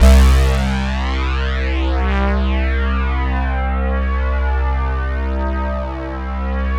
SYN FAT   00.wav